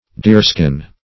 \Deer"skin`\